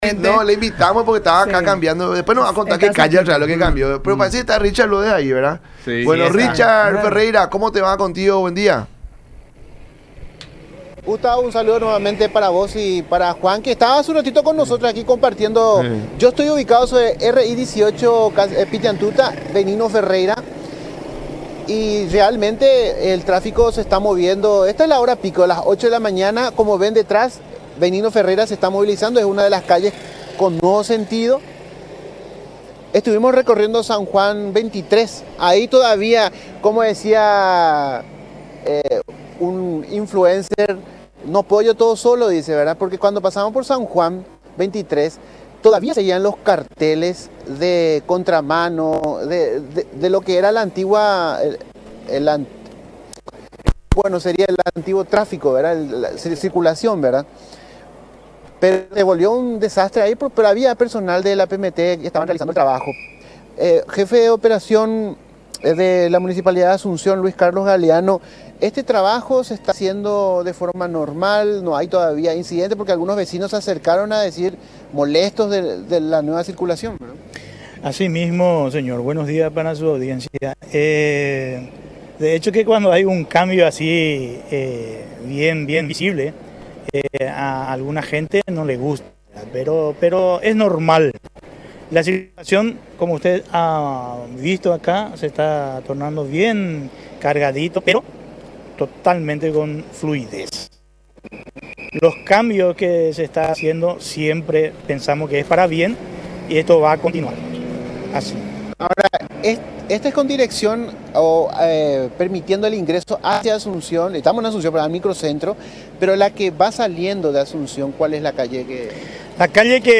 conversar con el programa “La Mañana de Unión” por Unión TV y Radio La Unión.